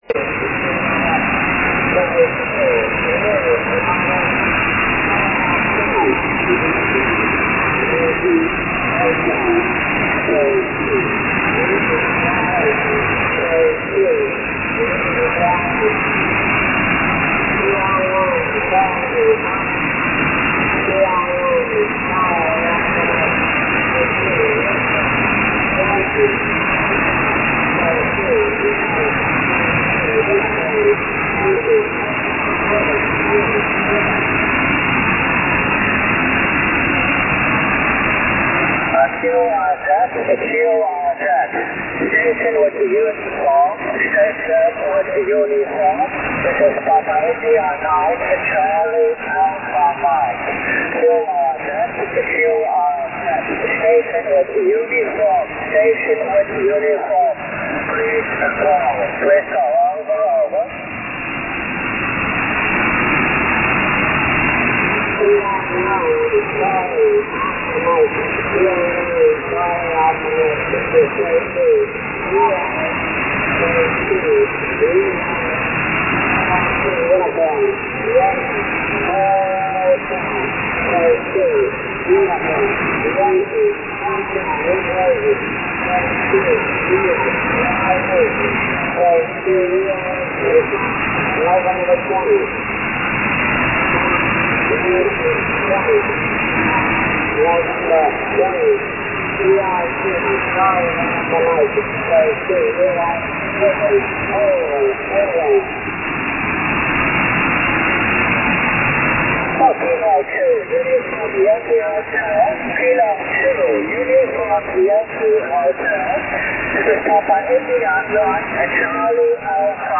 Слышал еще 7 корреспондентов во время их связи с бигганами, но мои общие вызовы результата не дали: